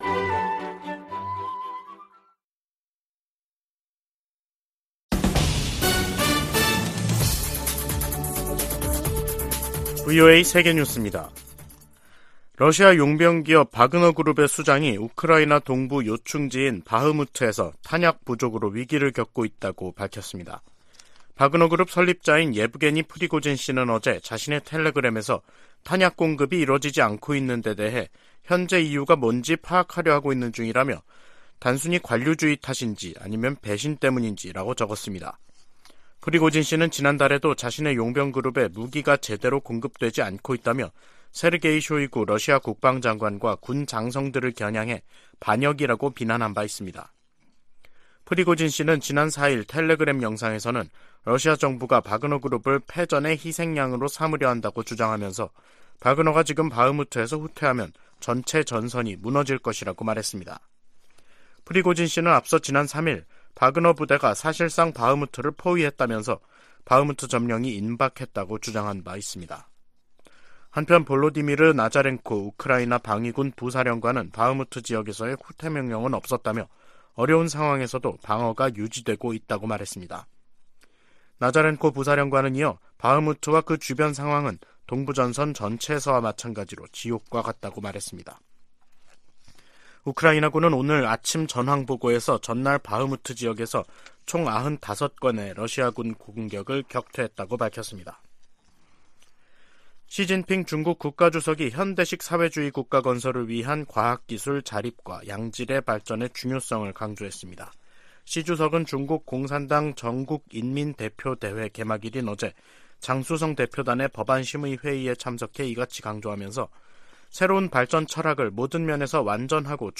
VOA 한국어 간판 뉴스 프로그램 '뉴스 투데이', 2023년 3월 6일 2부 방송입니다. 한국 정부가 일제 강제징용 해법으로 피해자들에게 국내 재단이 대신 배상금을 지급한다는 결정을 내렸습니다.